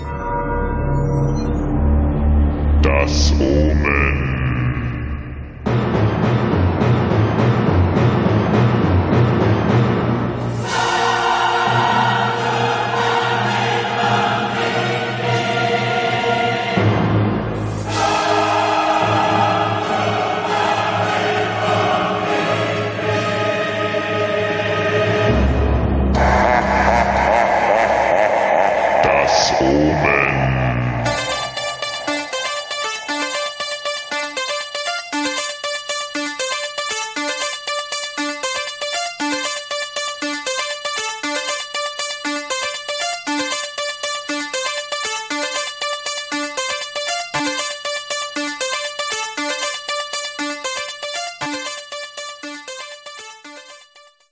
mixed & remixed by various DJs